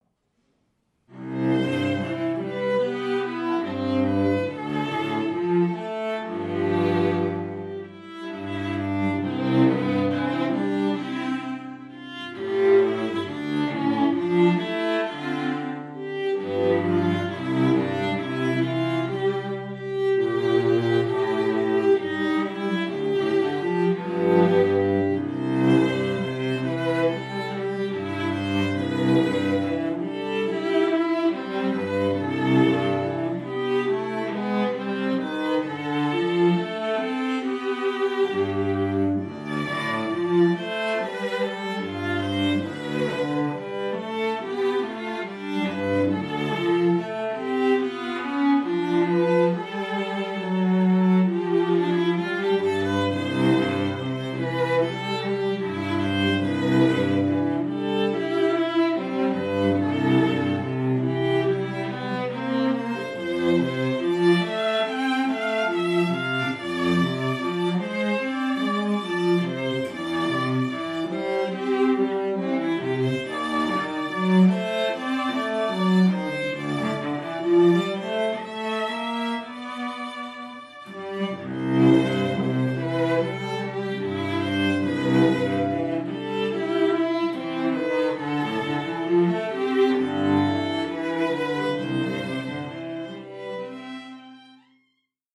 Duo: Violin & Cello – Contemporary